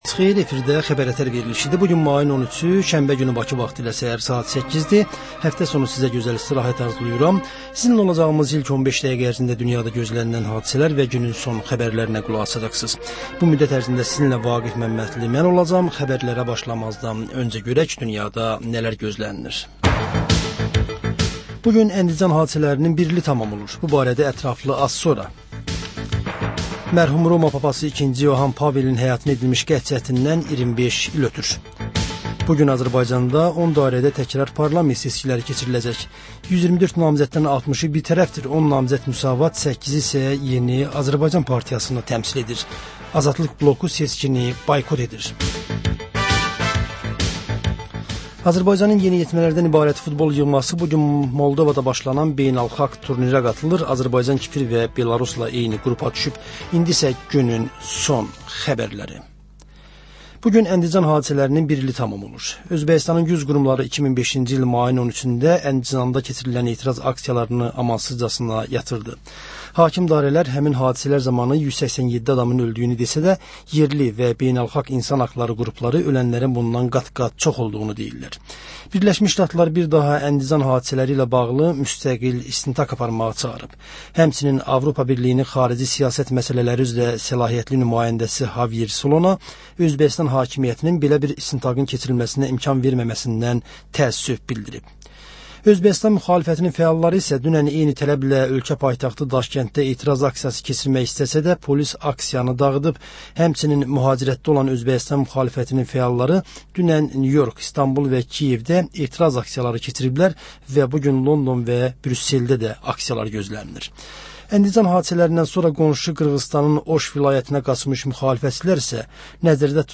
S əhər-səhər, Xəbər-ətər: xəbərlər, reportajlar, müsahibələrVə: Canlı efirdə dəyirmi masa söhbətinin təkrarı.